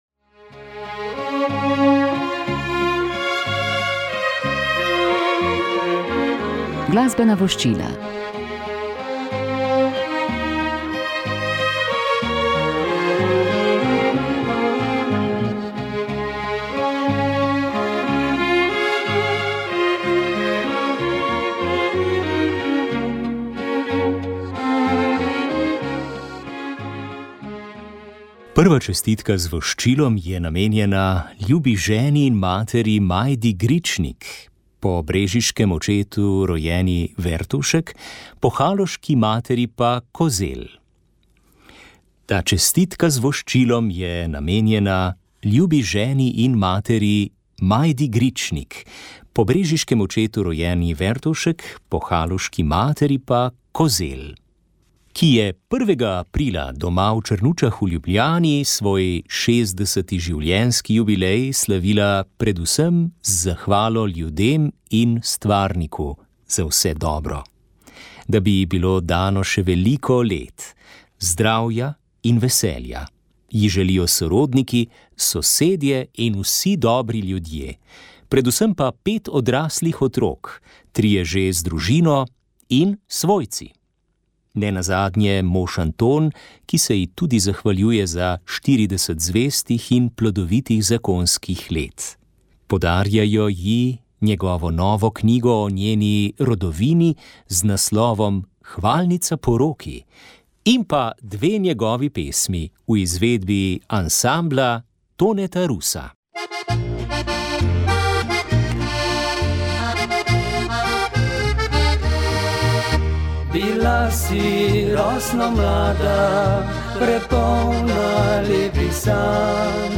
Tokrat o koncu šolskega leta, dejavnem preživljanju počitnic, počitnikovanju v družini, počitniškem delu, iskanju mladih in tudi o tem, zakaj se mladi ne odločajo za duhovniški poklic. Gostje v našem studiu so bili vzgojitelji in sopotniki mladih